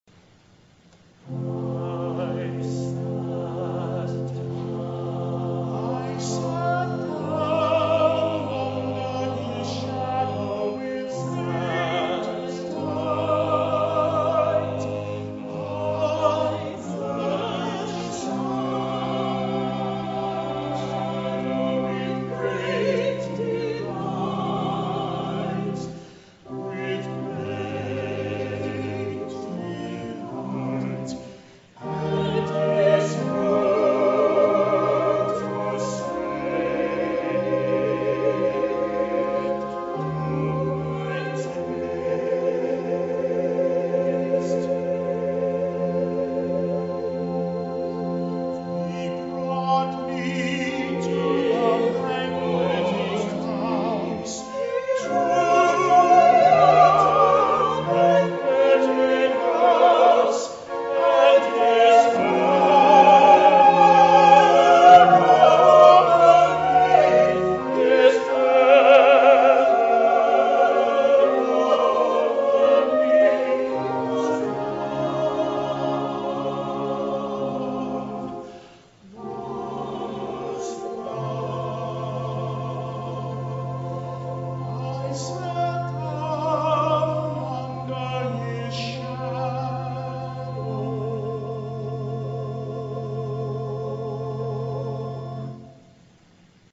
soprano
mezzo-soprano
tenor
baritone